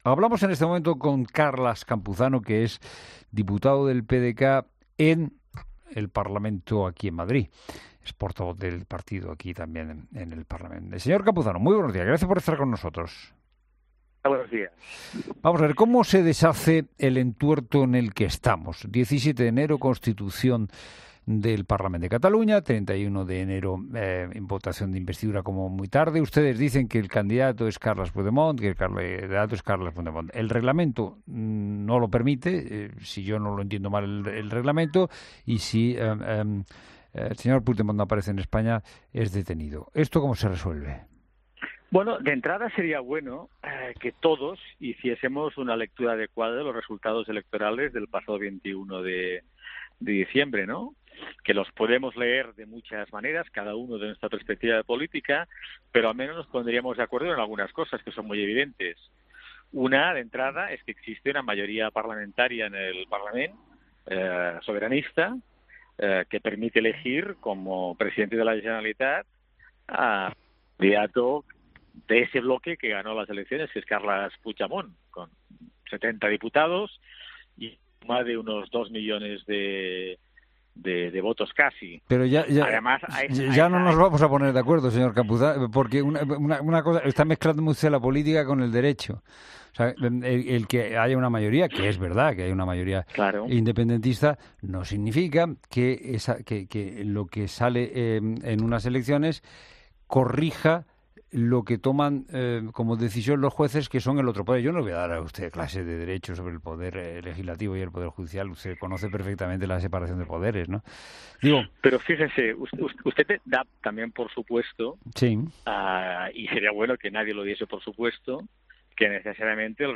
Carles Campuzano, portavoz del PdeCAT en el Congreso, en 'La Mañana de Fin de Semana'
Entrevista política